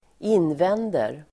Uttal: [²'in:ven:der]